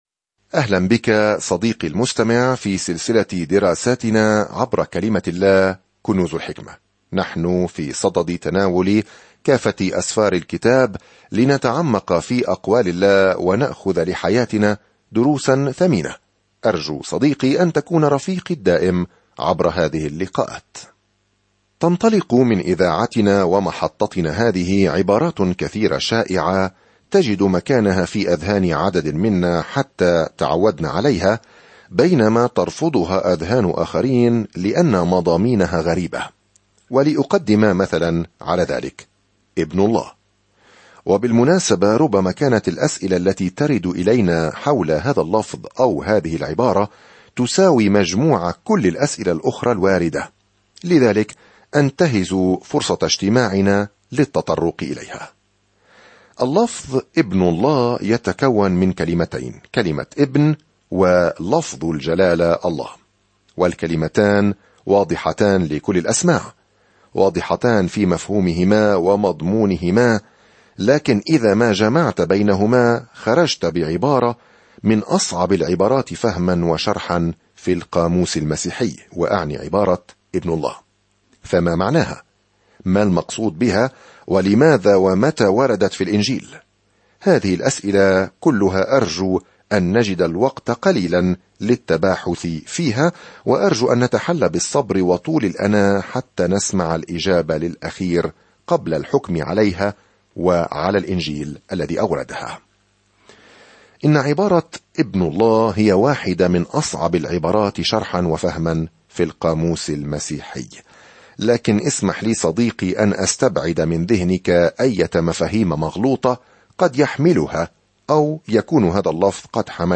الكلمة يُوحَنَّا ٱلثَّانِيَةُ 1 ابدأ هذه الخطة يوم 2 عن هذه الخطة تساعد هذه الرسالة الثانية من يوحنا امرأة كريمة وكنيسة محلية على معرفة كيفية التعبير عن الحب ضمن حدود الحق. سافر يوميًا عبر رسالة يوحنا الثانية وأنت تستمع إلى الدراسة الصوتية وتقرأ آيات مختارة من كلمة الله.